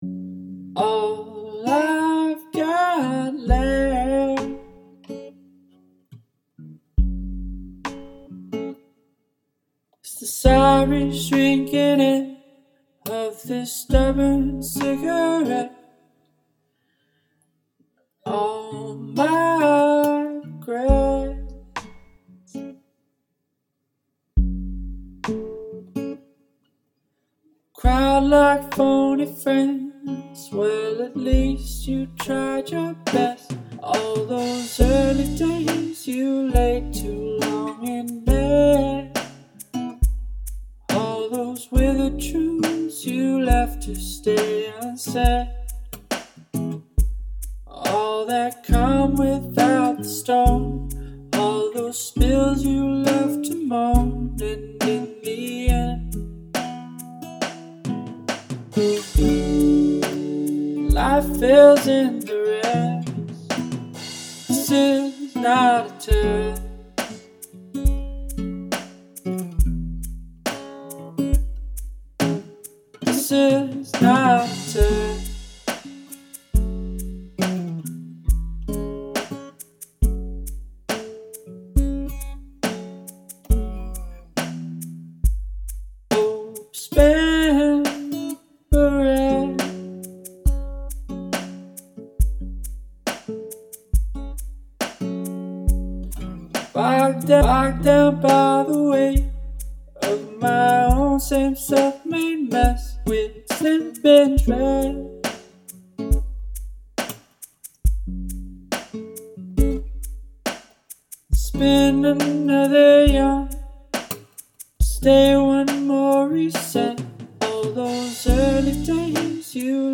Sounds like it starts and stops all the time.
That being said, this is a nice harmless little tune.
Needs more reverb.